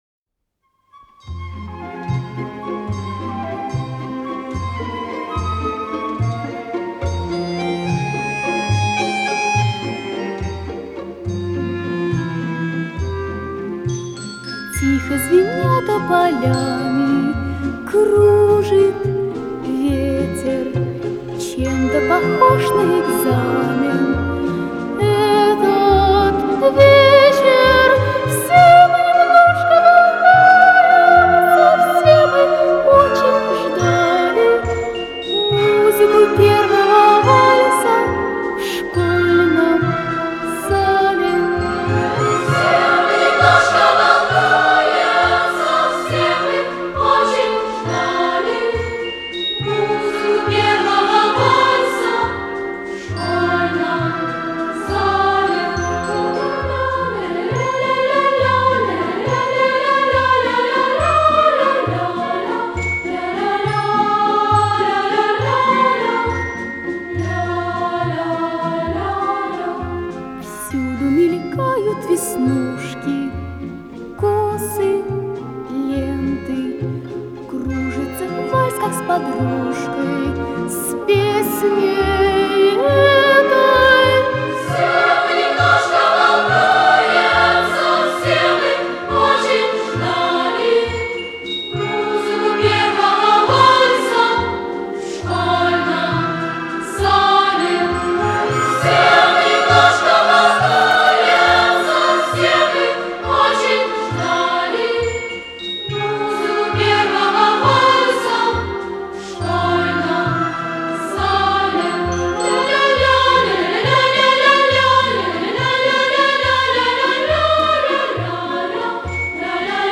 в исполнении детского хора